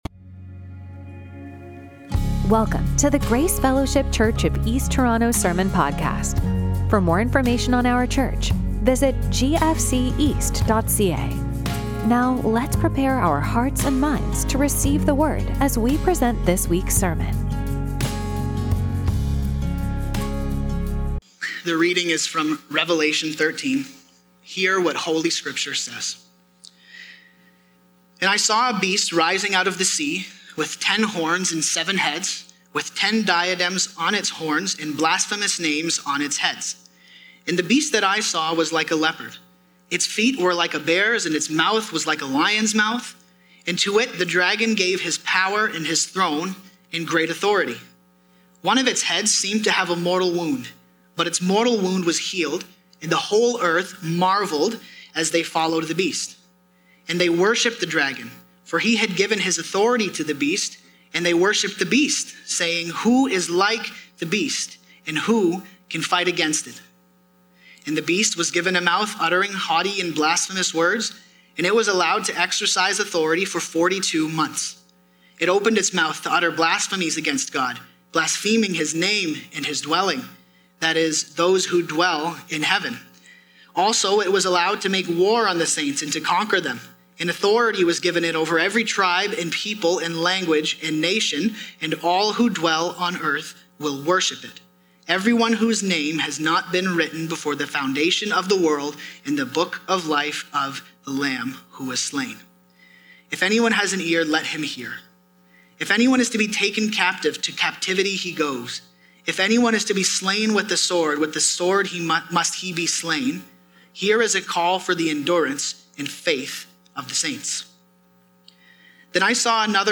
Sermons from Grace Fellowship Church East Toronto